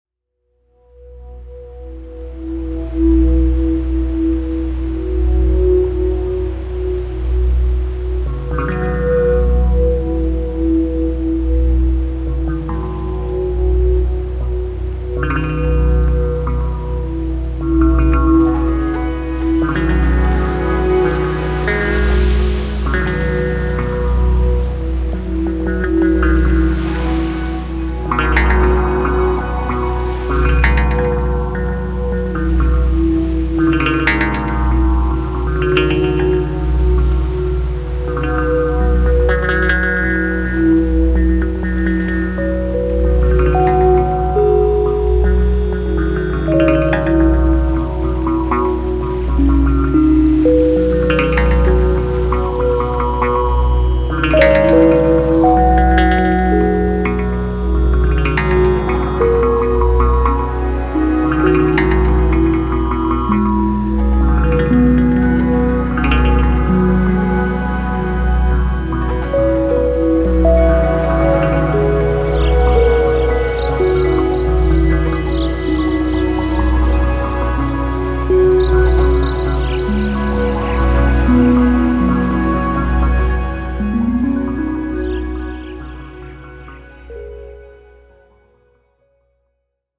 Electronix House Dub